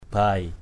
bai.mp3